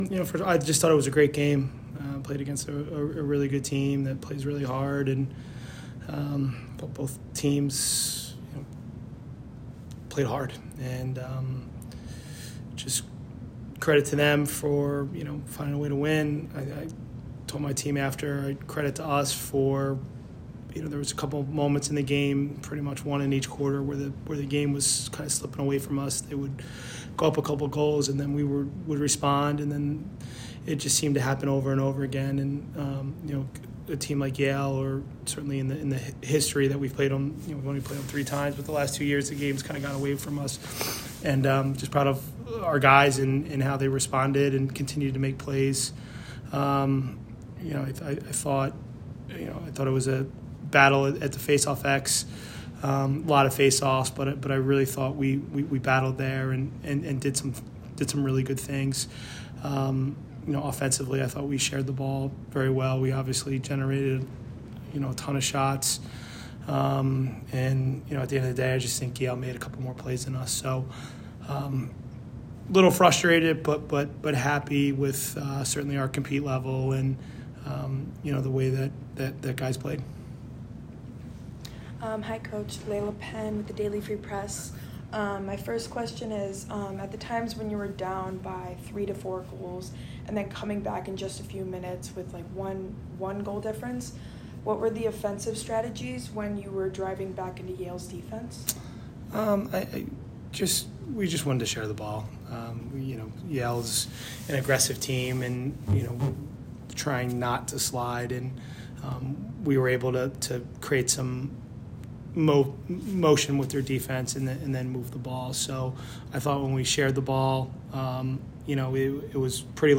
Men's Lacrosse / #8/9 Yale Postgame Interview (4-9-24) - Boston University Athletics